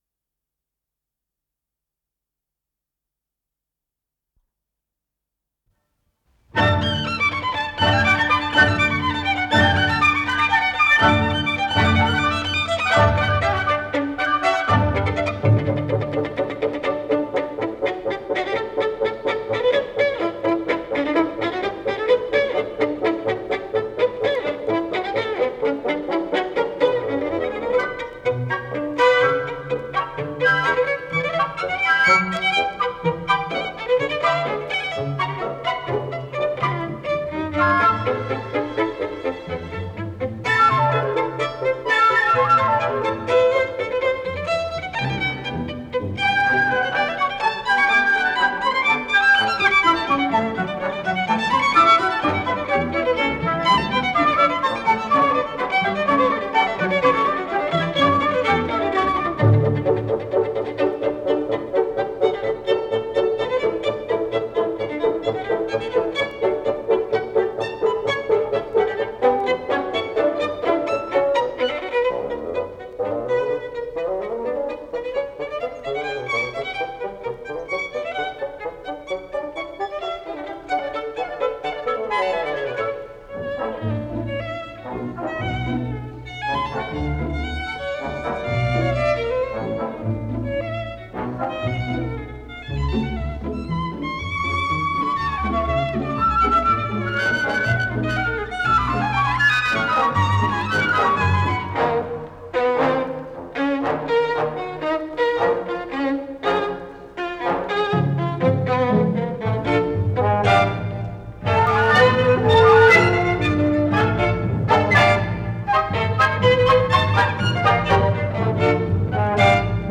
с профессиональной магнитной ленты
ре мажор
скрипка